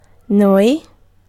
Ääntäminen
Ääntäminen : IPA : /wiː/ : IPA : /wi/ US : IPA : [wi] UK : IPA : [wiː]